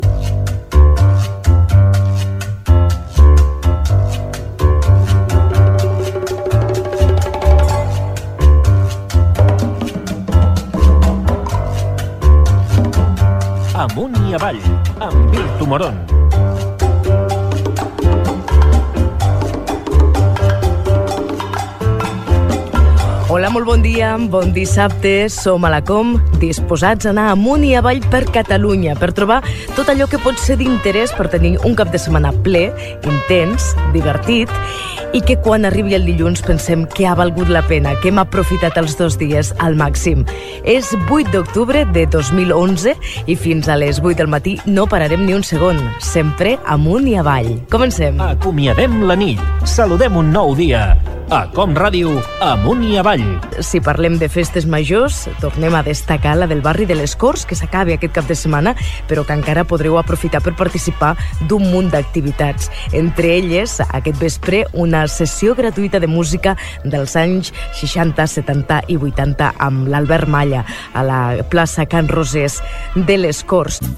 Indicatiu del programa i inici del programa amb agenda cultural i d'oci
Entreteniment
FM